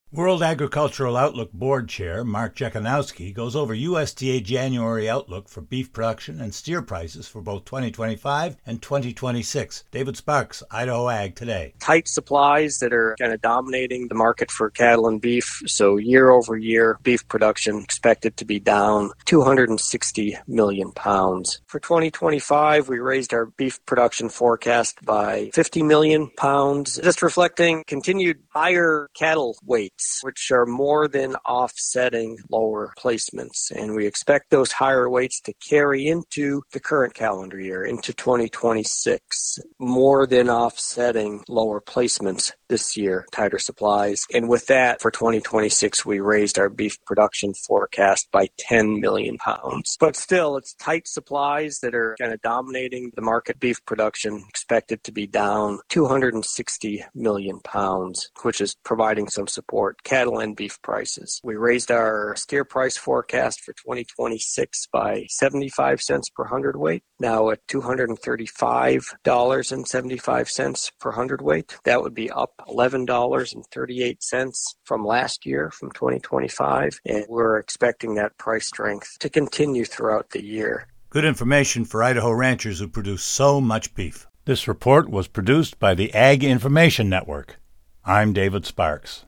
Beef report
World Agricultural Outlook Board Chair Mark Jekanowski goes over USDA January outlook for beef production and steer prices for both 2025 and 2026.